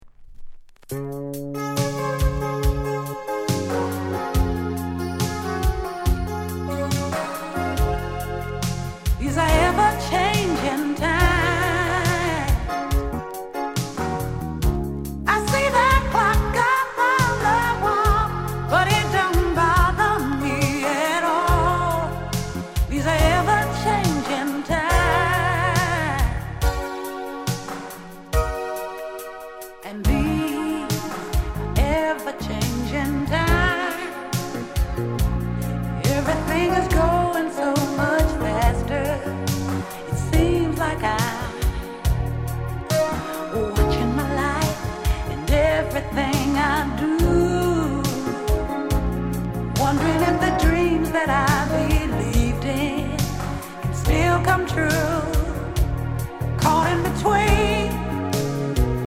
SOUND CONDITION EX-